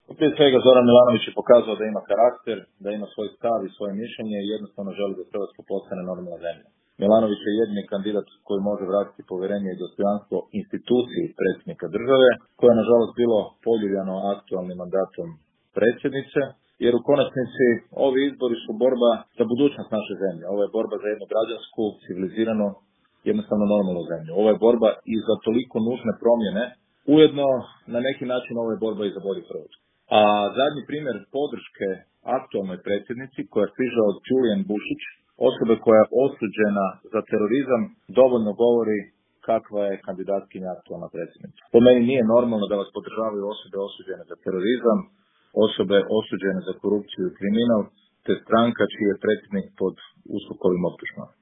Davor Bernardić u intervjuu Media servisa o aktualnoj predsjedničkoj kampanji